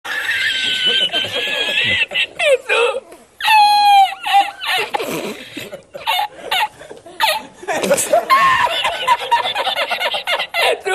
Risada El Risitas (Longa)
risada-el-risitas-longa.mp3